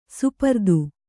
♪ supardu